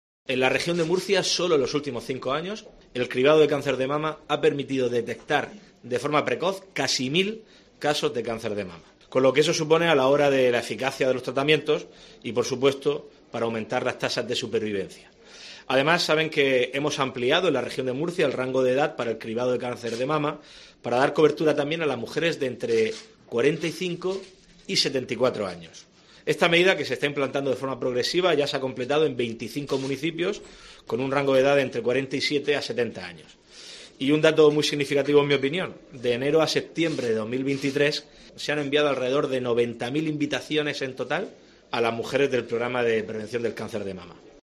Fernando López Miras, presidente de la Región de Murcia